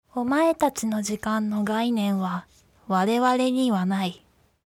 ボイス
キュート女性